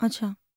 TALK 2.wav